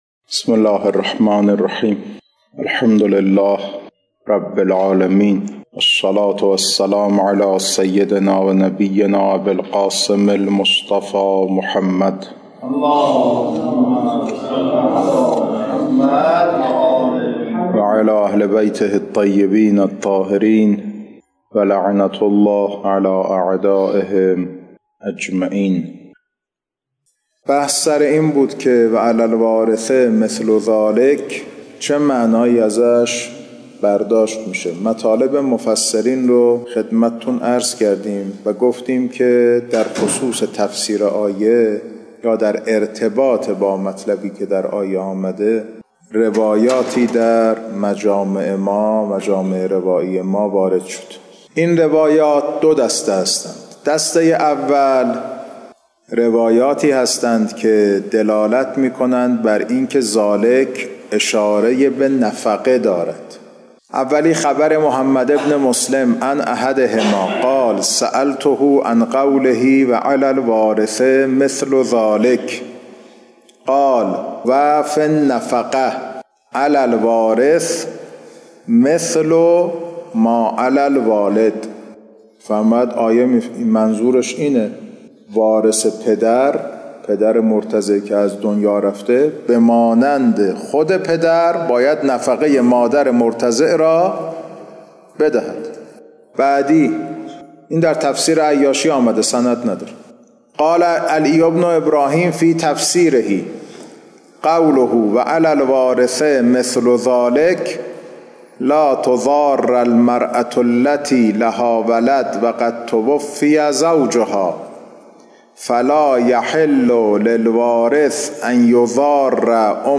خارج فقه